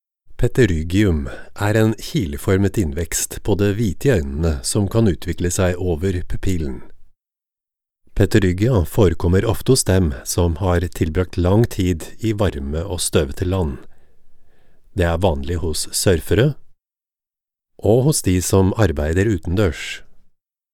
Norveççe Seslendirme
Erkek Ses